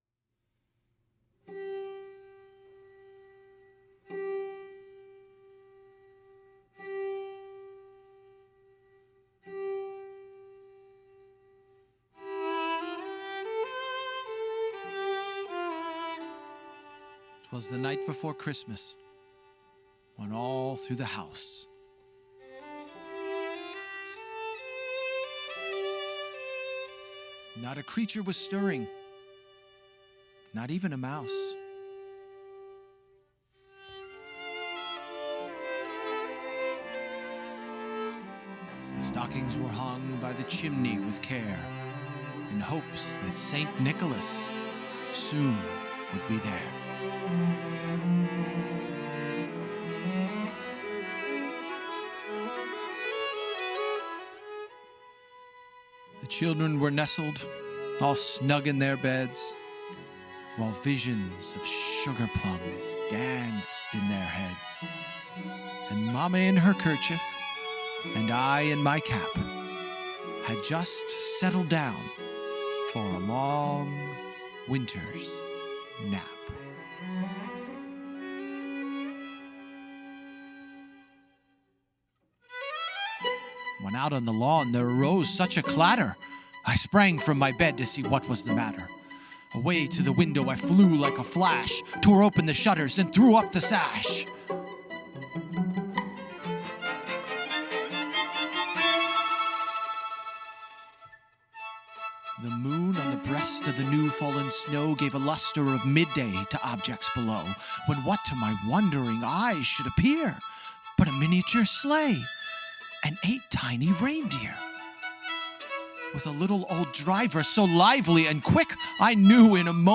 charming and lively